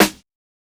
• Reverb Acoustic Snare Sound D# Key 24.wav
Royality free snare drum sample tuned to the D# note. Loudest frequency: 2704Hz
reverb-acoustic-snare-sound-d-sharp-key-24-sNk.wav